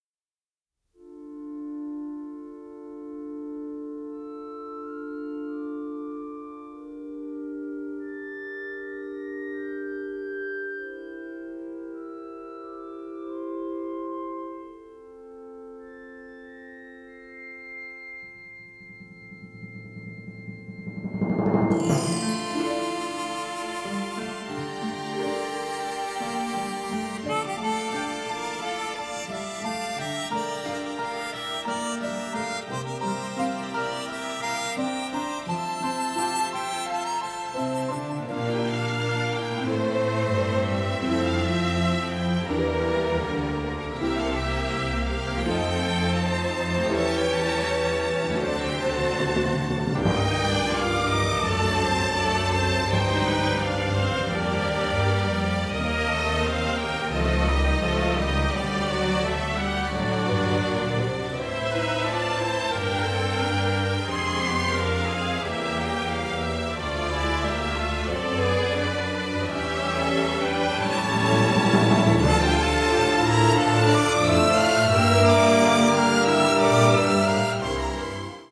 composed for full orchestra.